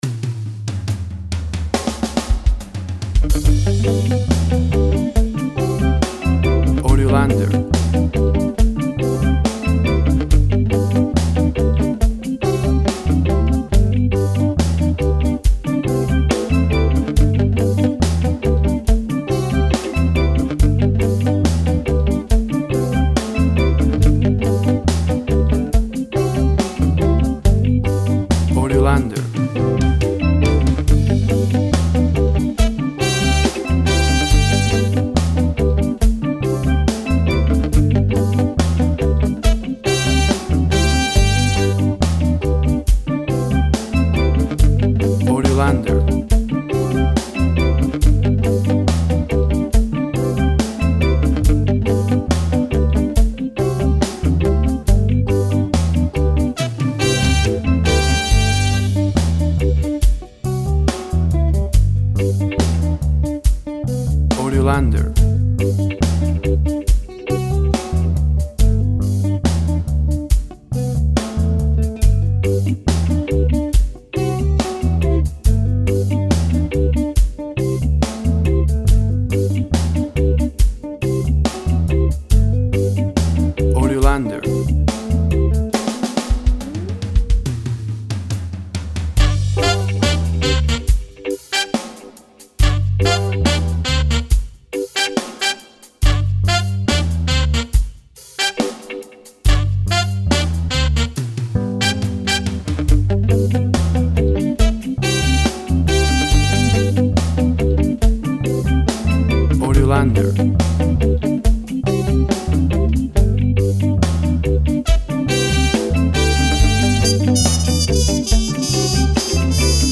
Inspiring Reggae Sounds to Jamaica and the Caribbean.
WAV Sample Rate 16-Bit Stereo, 44.1 kHz
Tempo (BPM) 70